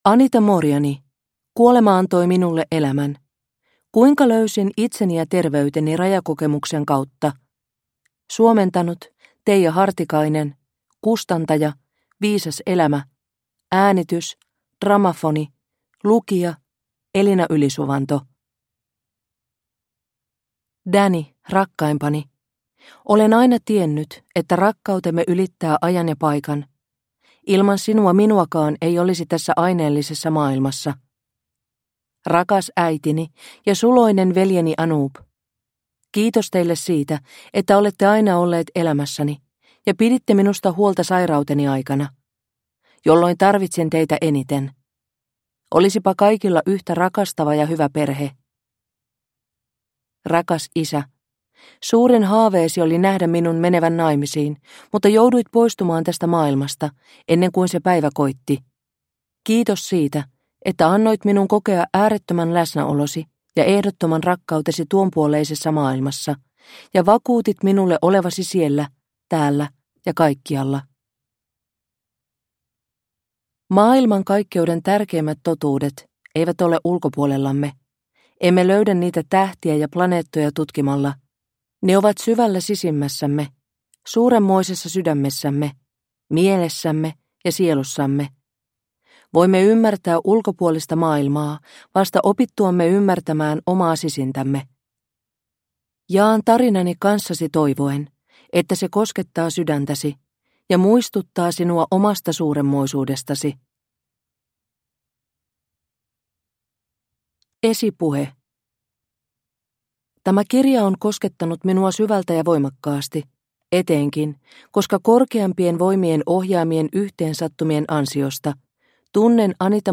Kuolema antoi minulle elämän – Ljudbok – Laddas ner